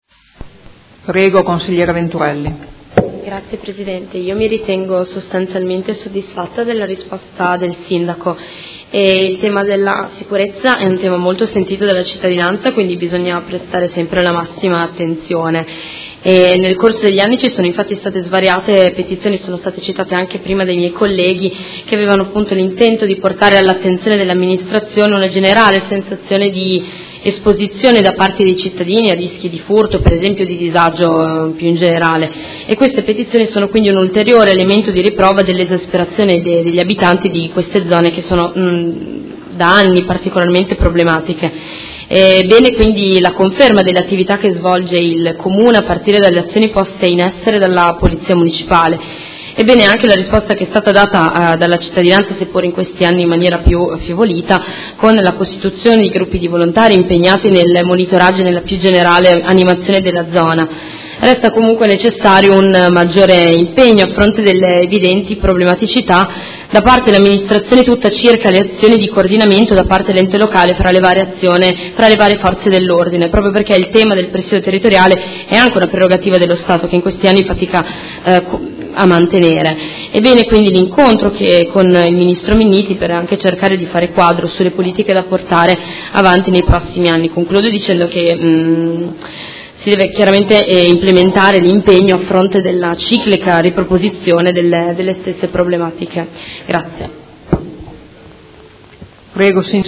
Seduta del 16/02/2017. Dibattito su interrogazione dei Consiglieri Venturelli, Stella e Carpentieri (P.D.) avente per oggetto: Problematiche zona R-nord - Viale Gramsci – e Parco XII Aprile.